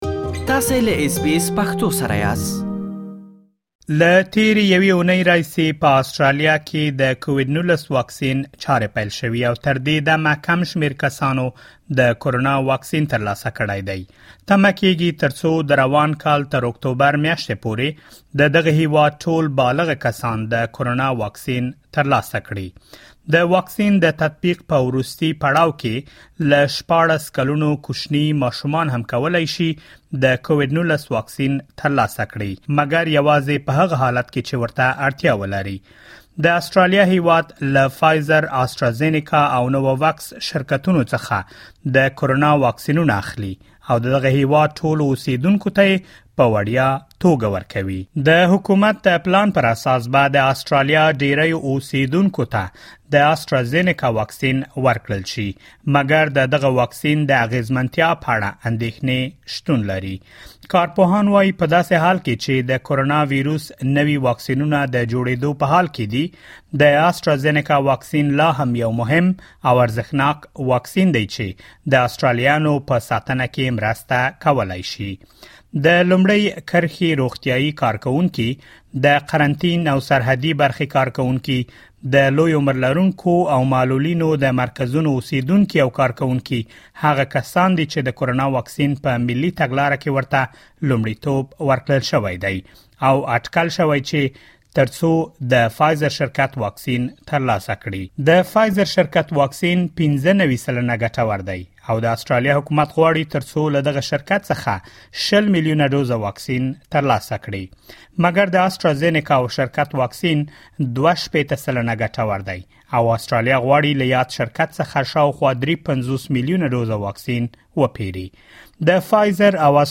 رپوت